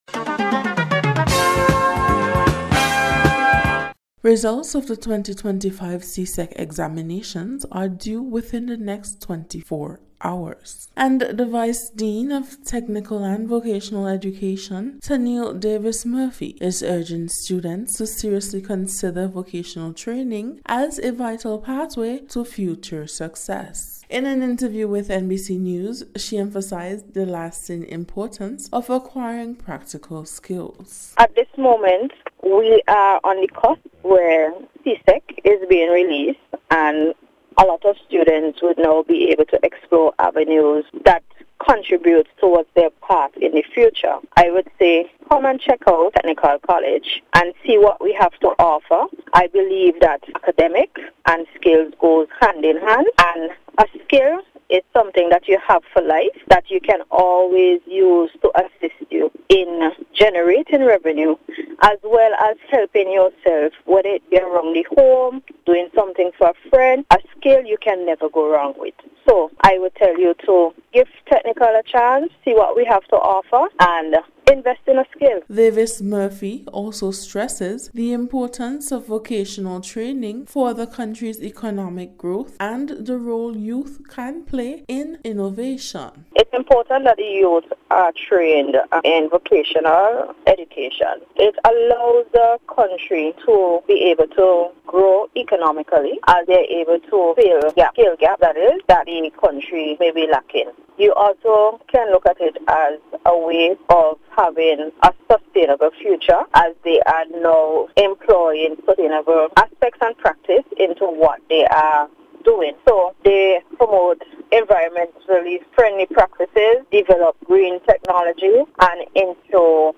NBC’s Special Report- Thursday 14th August,2025